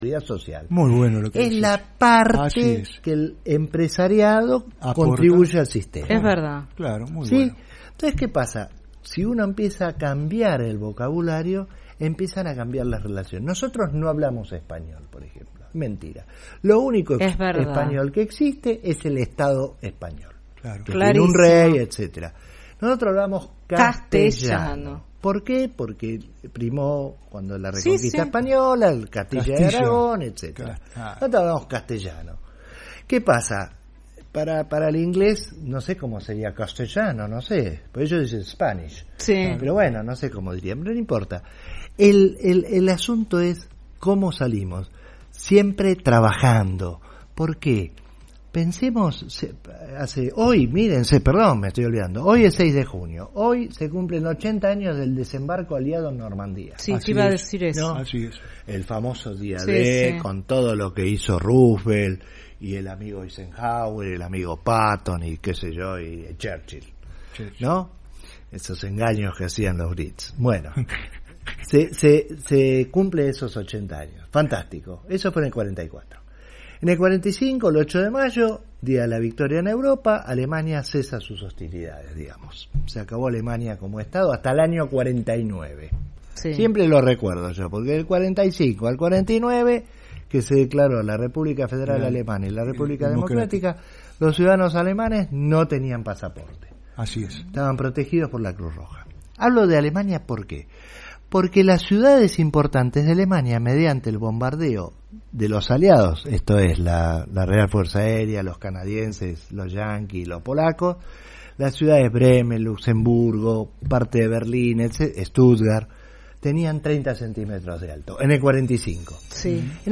Dialogamos con el médico veterinario y ex rector de la Universidad de Buenos Aires, Rubén Eduardo Hallú sobre la situación de la universidad pública.